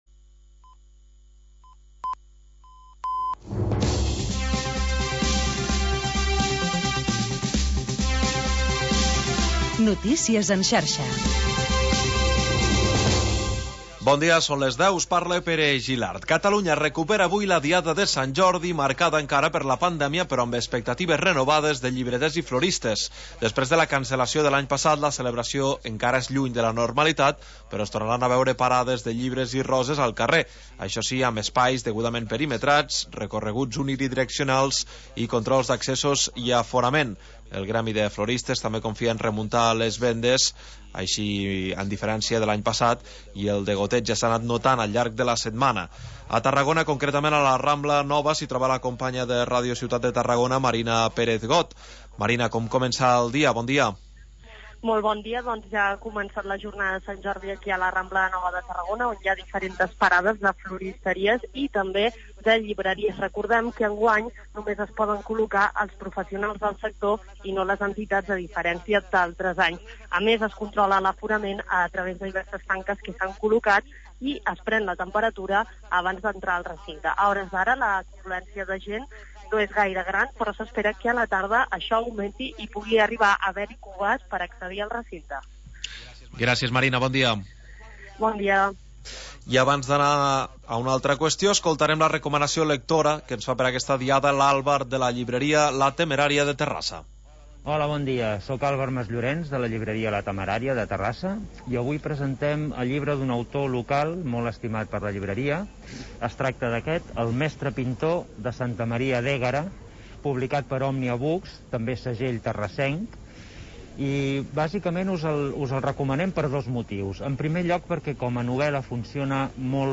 Magazin local d'entreteniment